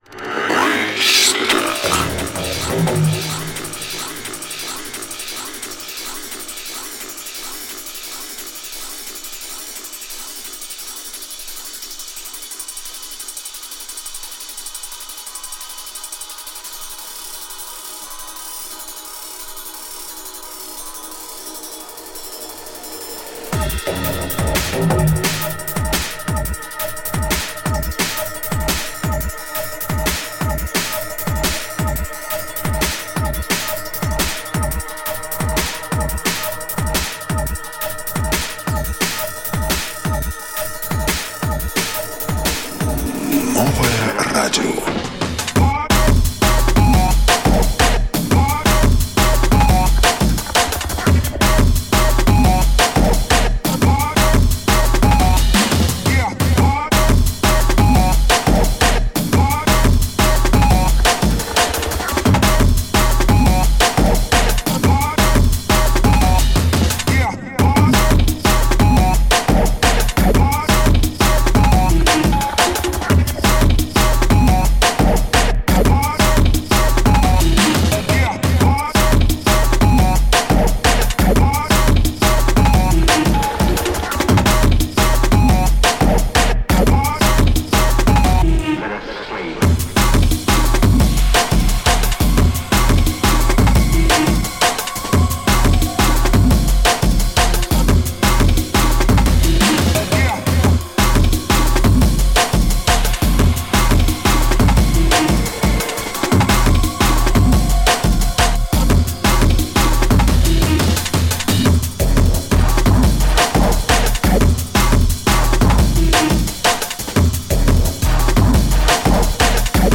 hardstep, jump up, liquid funk, neurofunk, the best